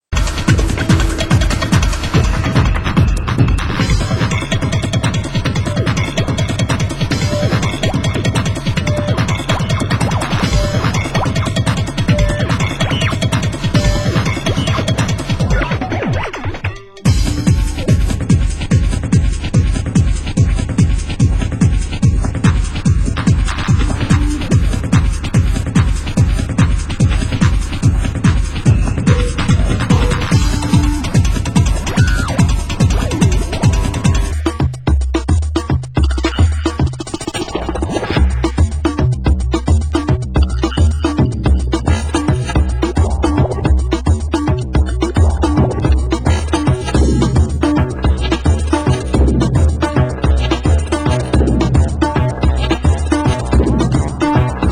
AccueilElectro / New grooves  >  Deep House